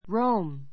Rome róum ロ ウ ム 固有名詞 ローマ ⦣ イタリアの首都.